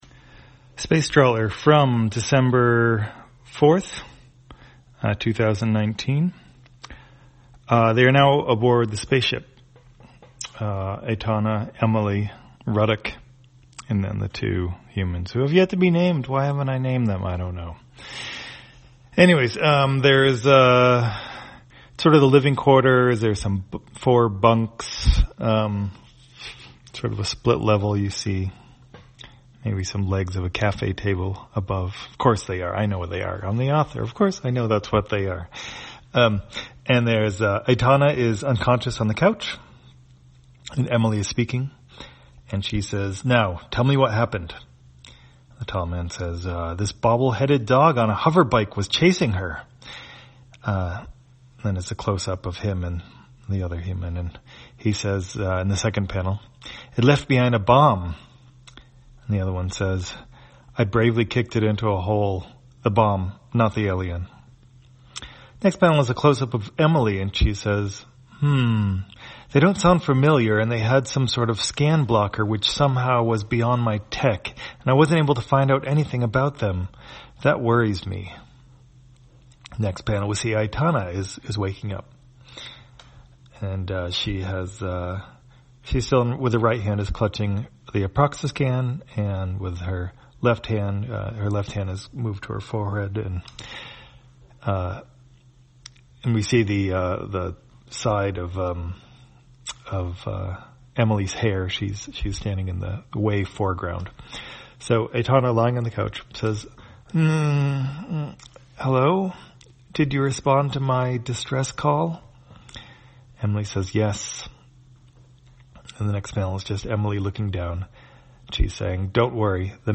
Spacetrawler, audio version For the blind or visually impaired, December 4, 2019.